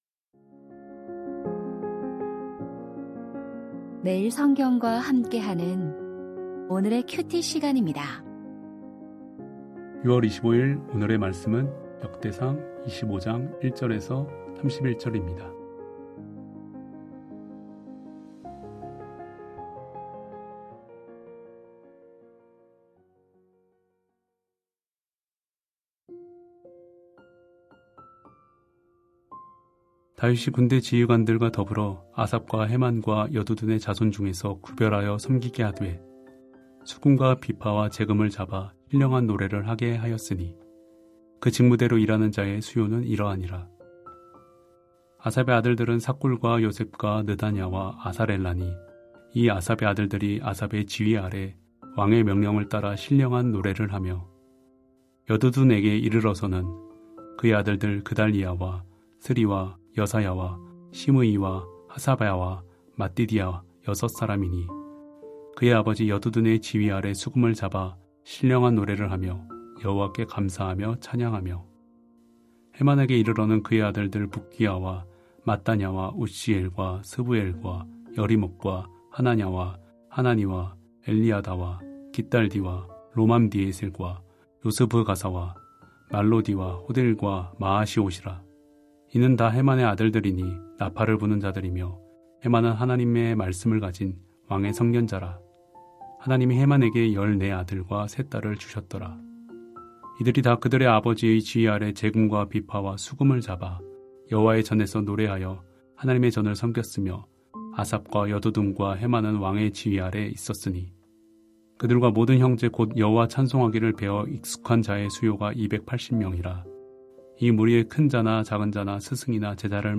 역대상 25:1-31 바람직한 사역의 전수 2025-06-25 (수) > 오디오 새벽설교 말씀 (QT 말씀묵상) | 뉴비전교회